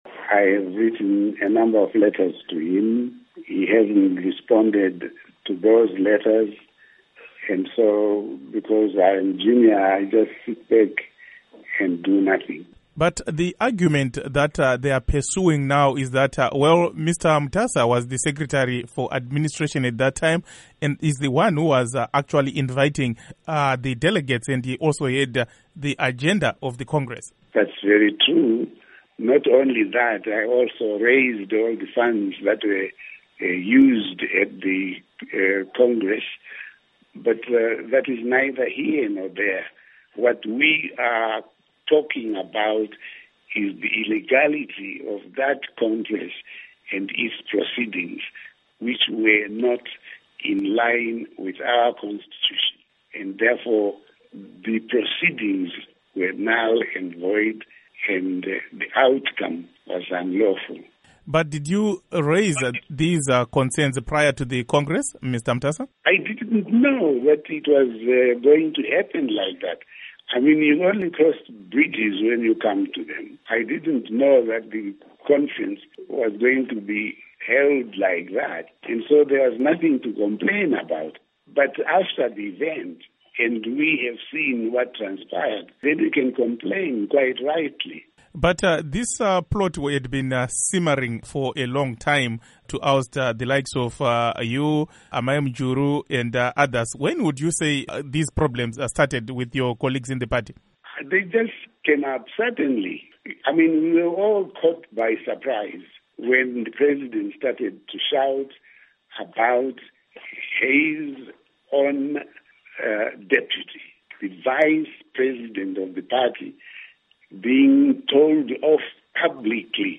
Interview With Didymus Mutasa on Mugabe, Zanu PF Friction